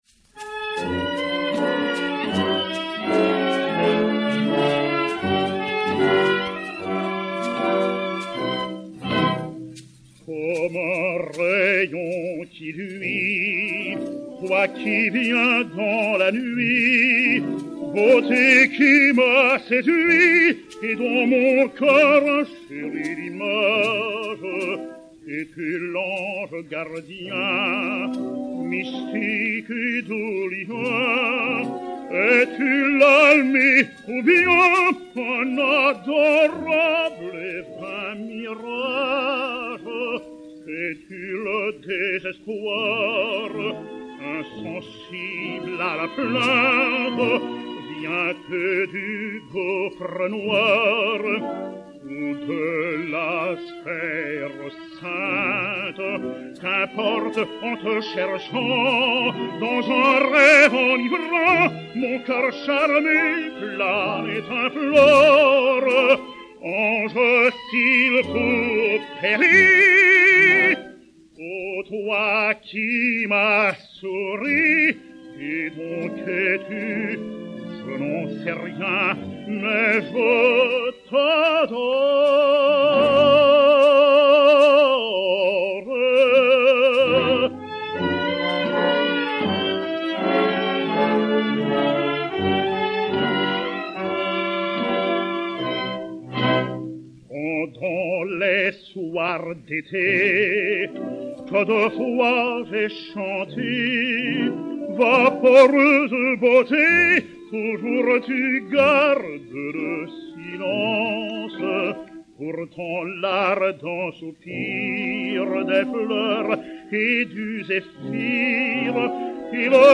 Mélodie
soprano, et Orchestre